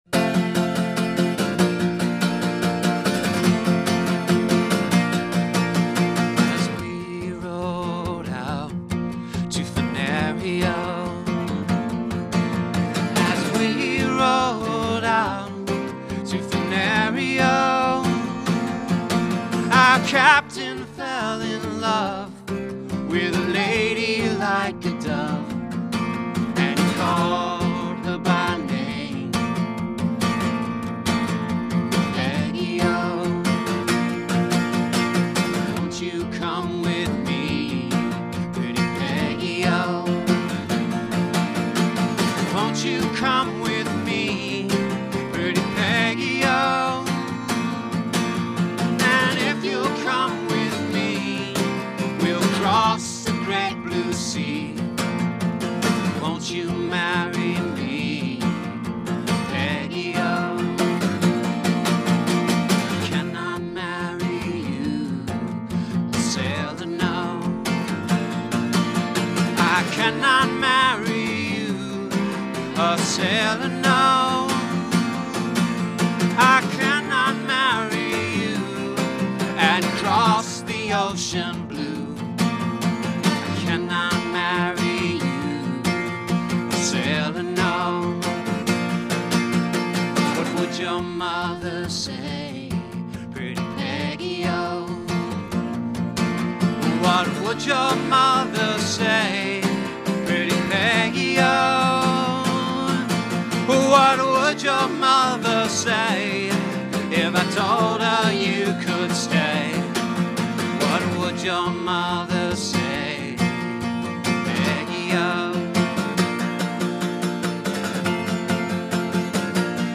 the duo gives folk songs a rock edge.